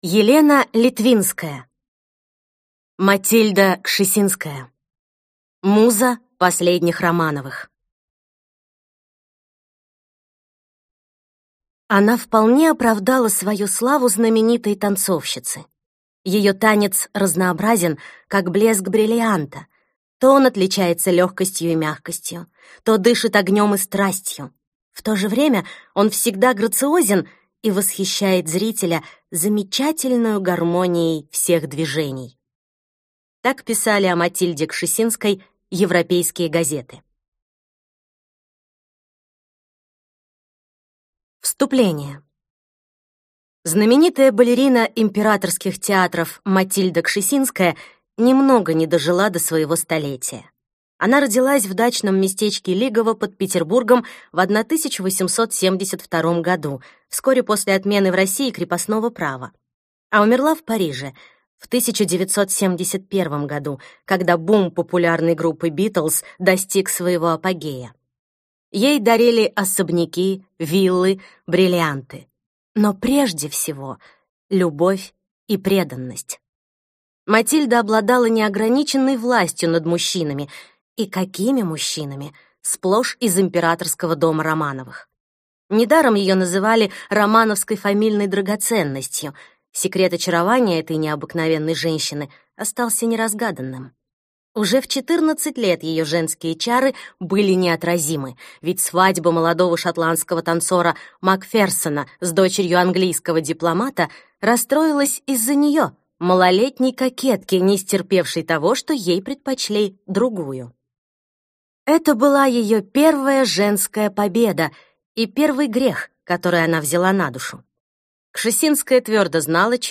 Аудиокнига Матильда Кшесинская. Муза последних Романовых | Библиотека аудиокниг